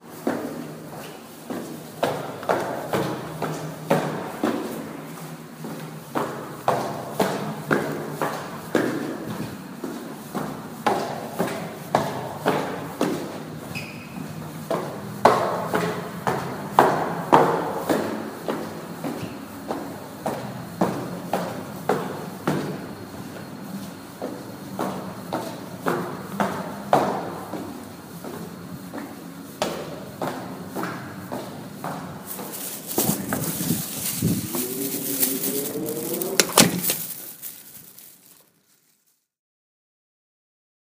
Staircase commute, Los Angeles, California